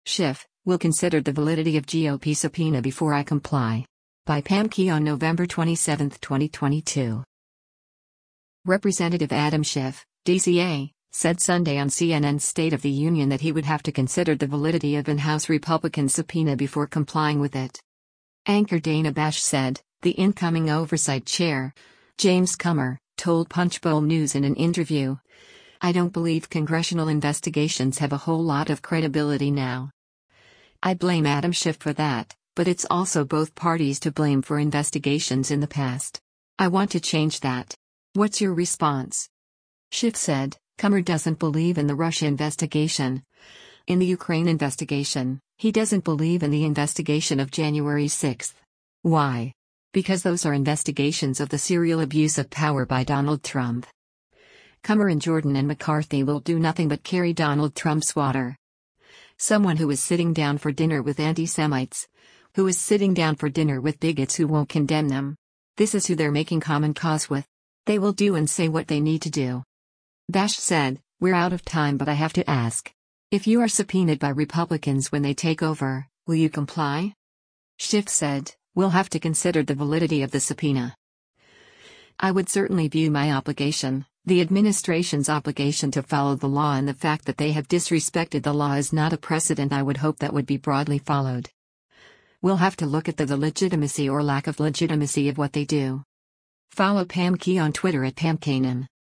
Representative Adam Schiff (D-CA) said Sunday on CNN’s “State of the Union” that he would have to consider the validity of and House Republicans’ subpoena before complying with it.